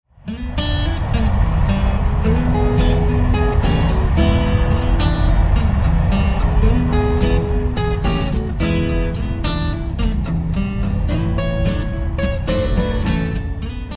Hear the difference, listen to an airplane sound simulation below:
Airplane Noise With and Without Plugfones